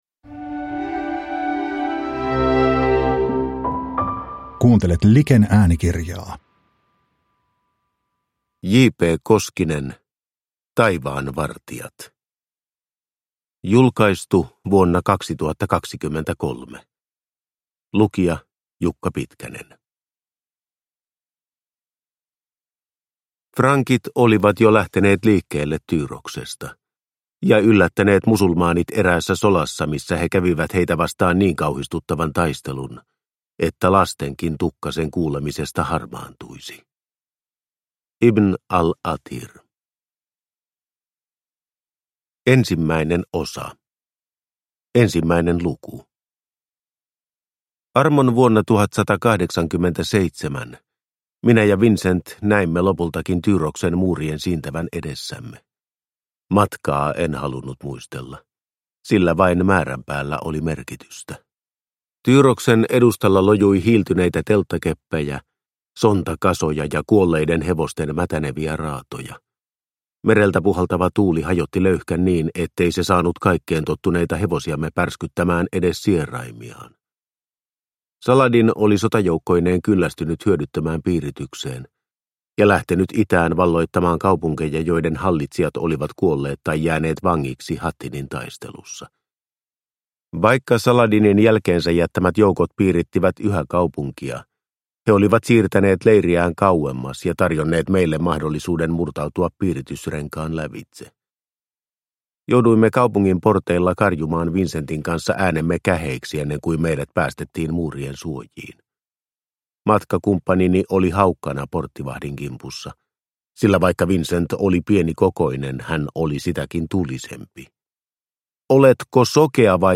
Taivaan vartijat – Ljudbok – Laddas ner